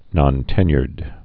(nŏn-tĕnyərd, -yrd)